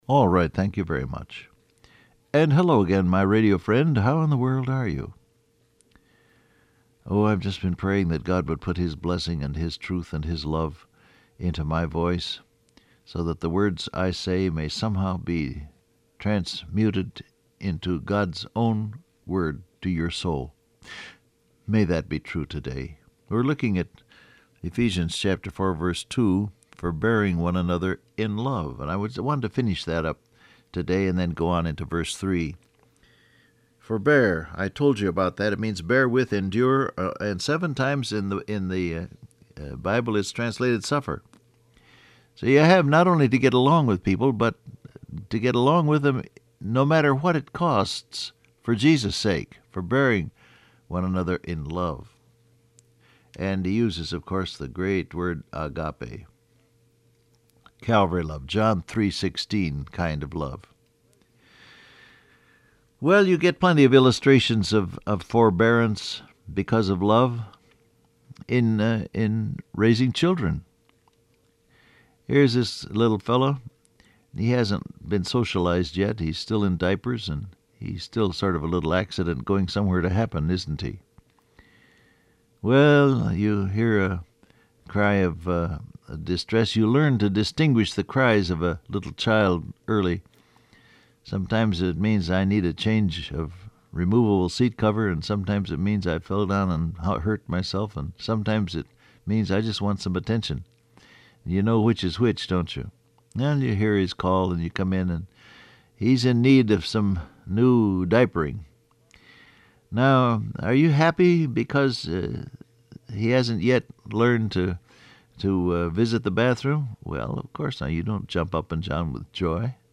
Download Audio Print Broadcast #7324 Scripture: Ephesians 4:3 , John 3:16 Topics: Patience , Love , Unity , Forbearance Transcript Facebook Twitter WhatsApp Alright, thank you very much.